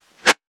weapon_bullet_flyby_07.wav